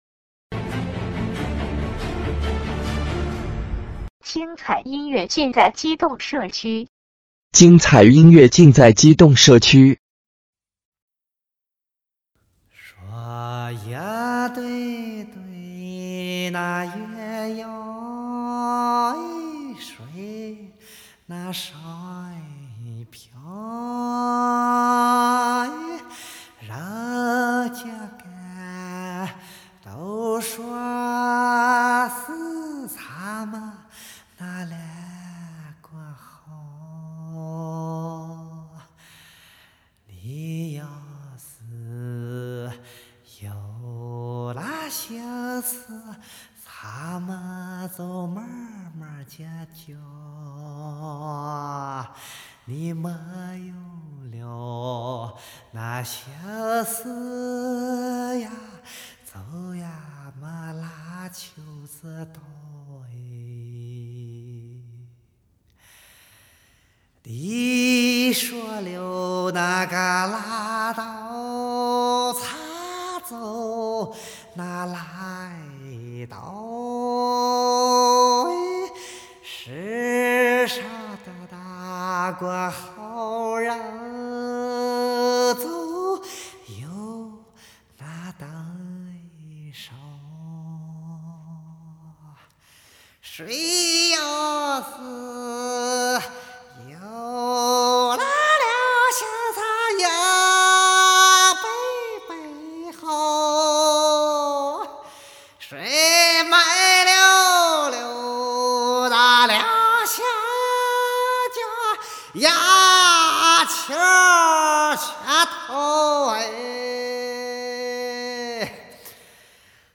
本专辑是无伴奏演唱专辑